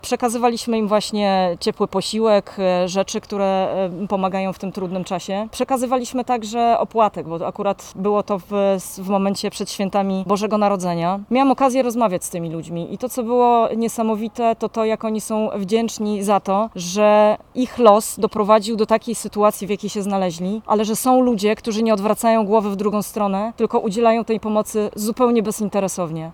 Komendant Straży Miejskiej w Warszawie, Magdalena Ejsmont wspominała swoje uczestnictwo w patrolu zajmującym się pomocą bezdomnym.